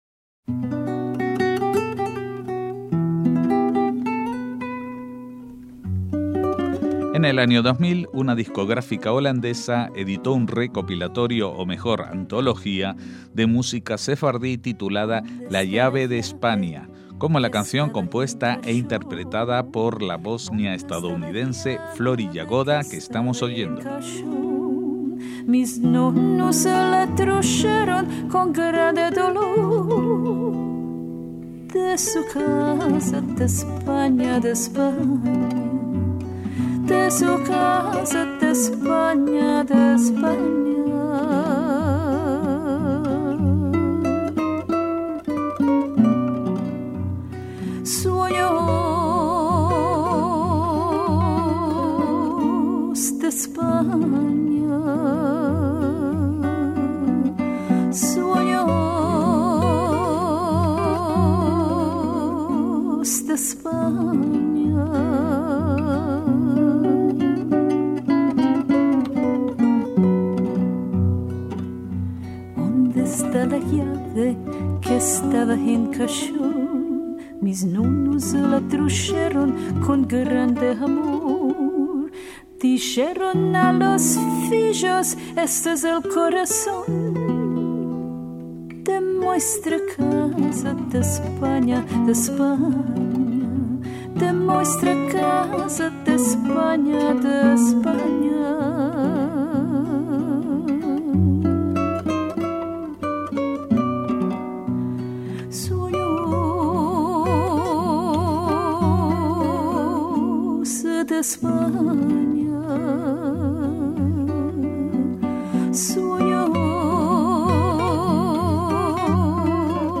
MÚSICA SEFARDÍ